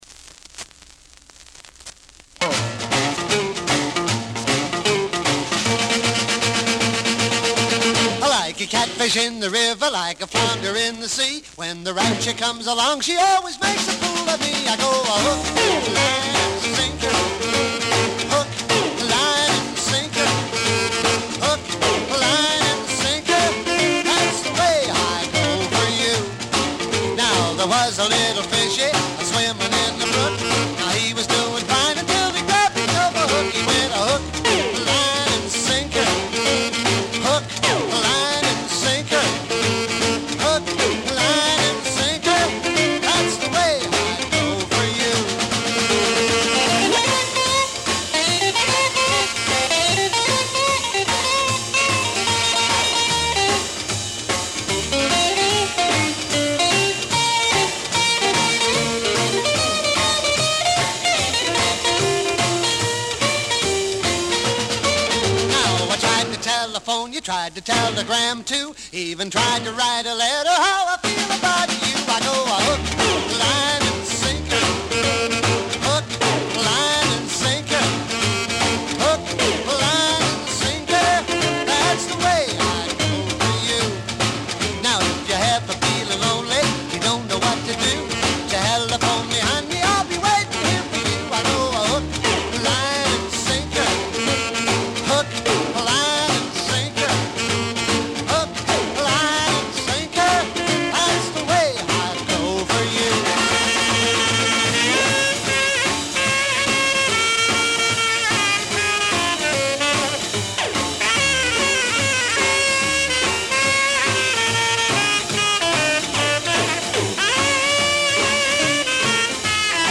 45 RPM vinyl record
were an American Rock and Roll band founded in 1952.